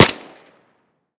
M16_sil-2.ogg